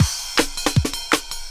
Shift_The_Snare_160.wav